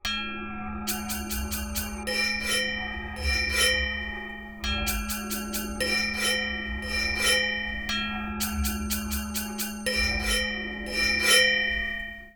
Sound Design
The sound shows a greater dynamic range contrast at this stage, and the sudden volume peak enhances the impact of anger. The inharmonious intervals increase the tense atmosphere, making the audience feel the aggressiveness and instability of anger in hearing.